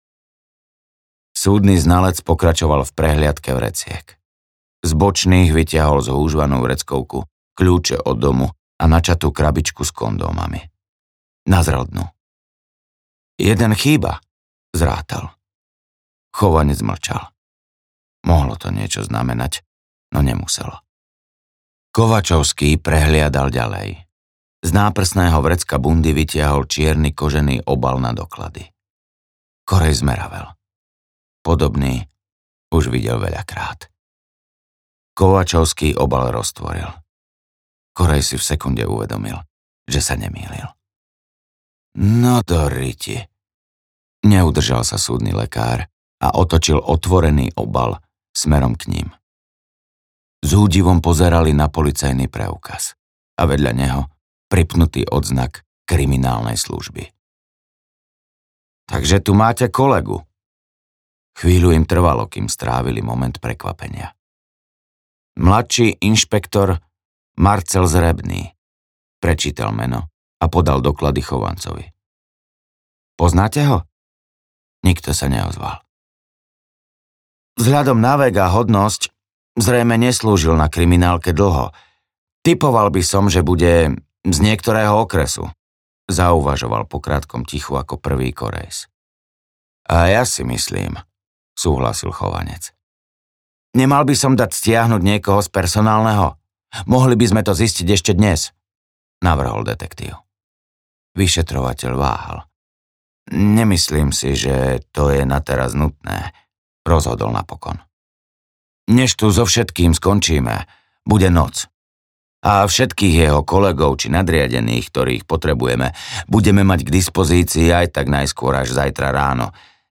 Príliš vysoká cena audiokniha
Ukázka z knihy